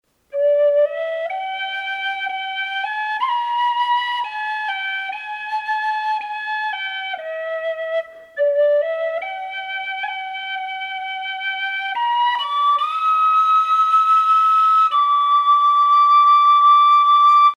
Die Instrumente sind zylindrisch gebaut und haben ein Kunststoffmundstück.
Die Instrumente sind mittellaut.
Diese Whistle hat einen eher zarten Klang.